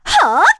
Erze-Vox_Attack4_kr.wav